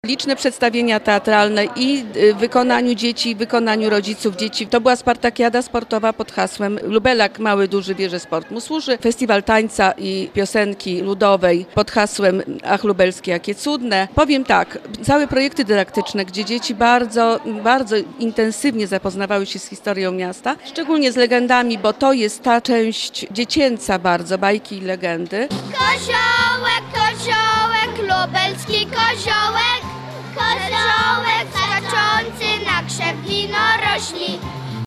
W Lublinie zorganizowano galę podsumowującą jubileusz 700-lecia miasta.